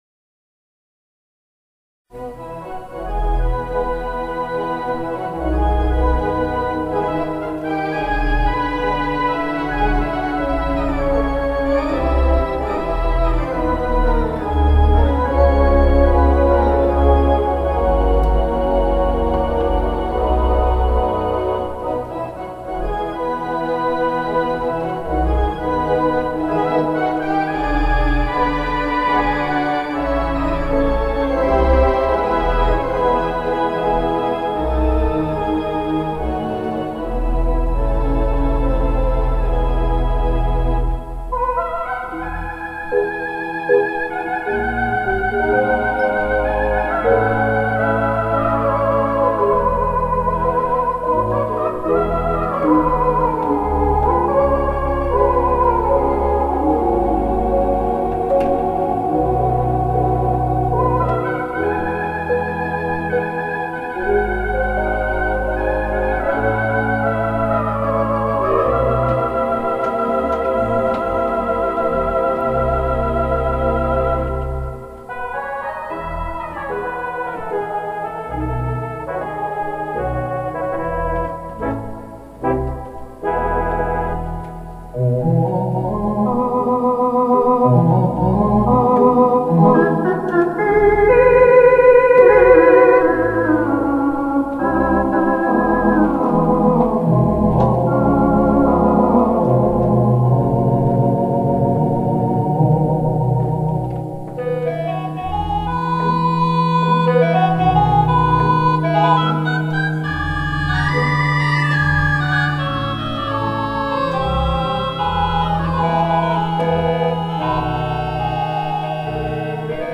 The music was recorded live